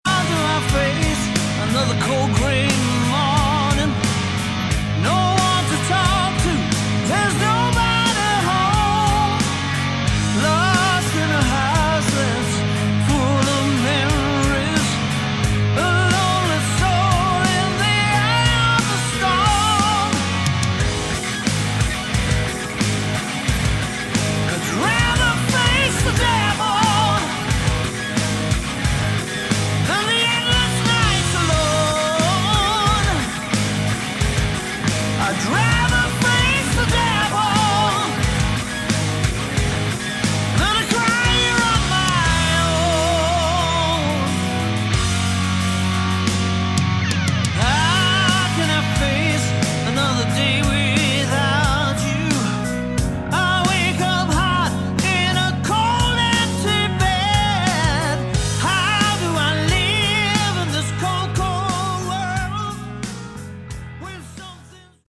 Category: Hard Rock
Vocals
Guitars